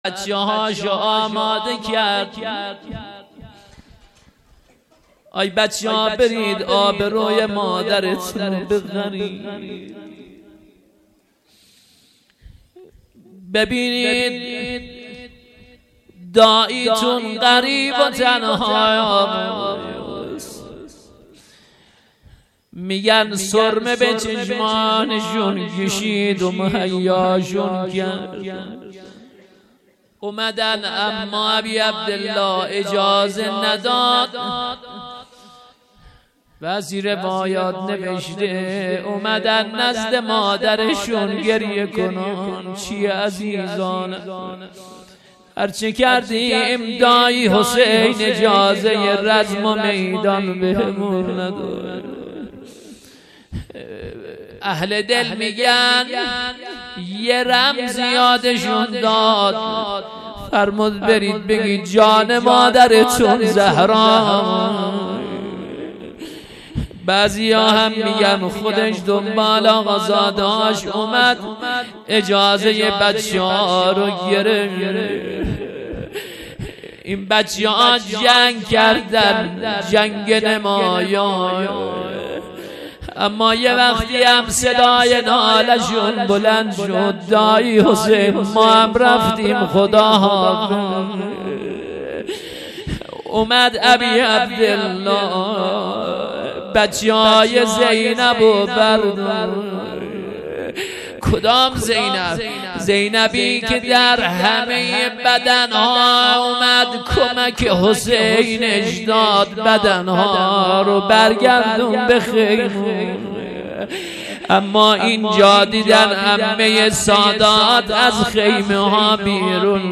روضه شب چهارم محرم96هیئت اباعبدالله